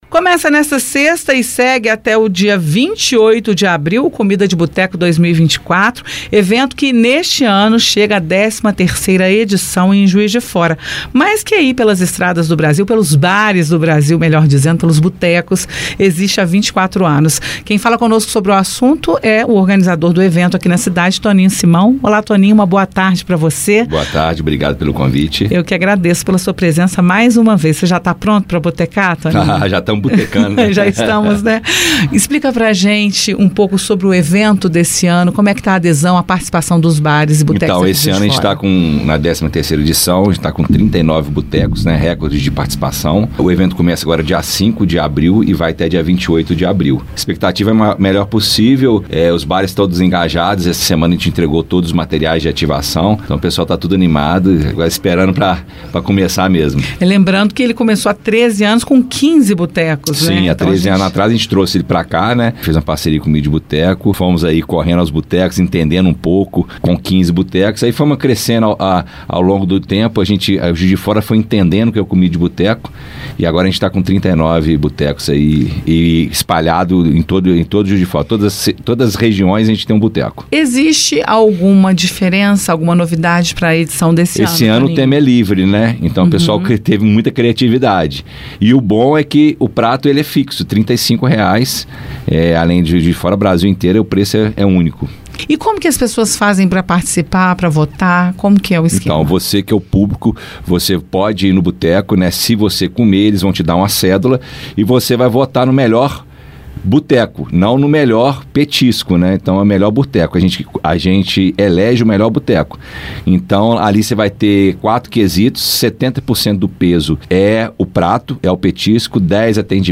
Itatiaia-Entrevista-Comida-di-Buteco.mp3